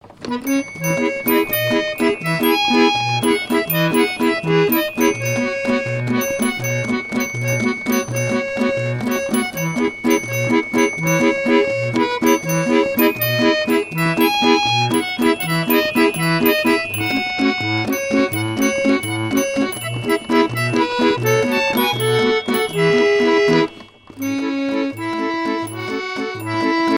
danse : valse
musique mécanique
Pièce musicale inédite